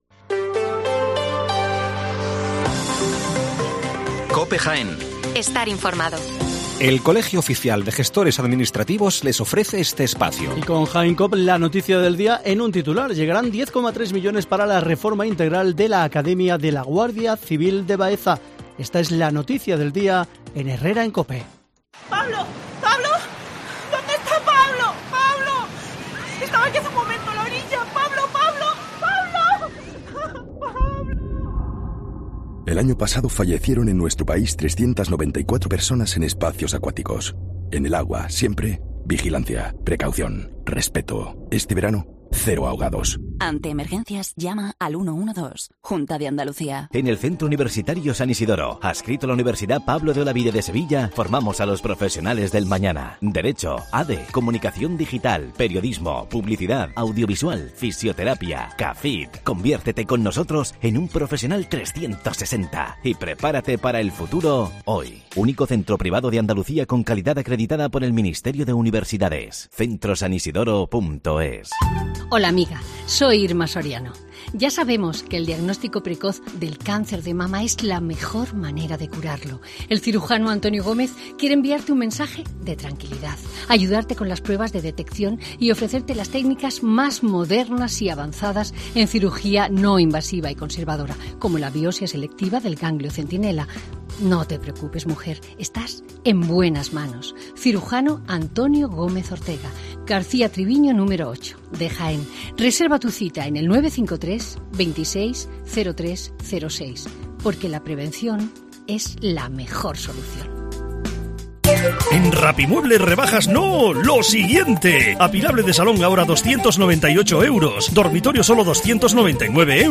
Las noticias matinales en Herrera en COPE 7:55 horas